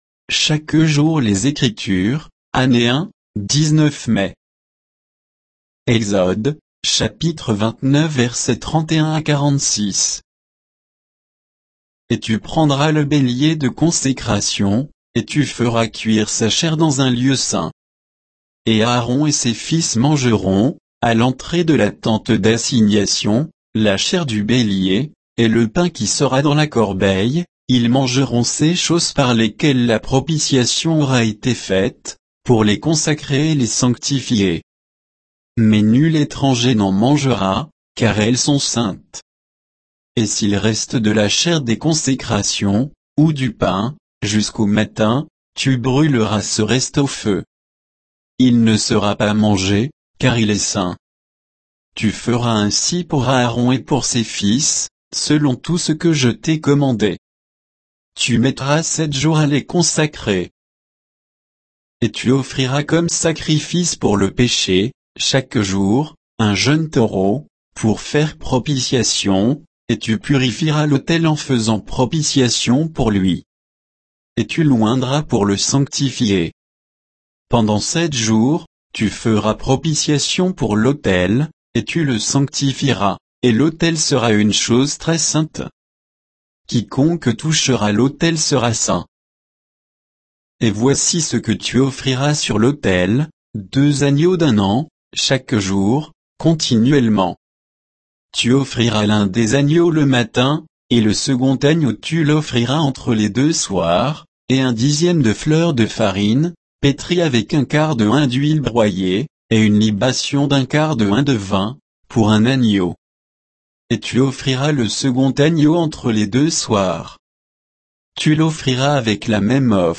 Méditation quoditienne de Chaque jour les Écritures sur Exode 29, 31 à 46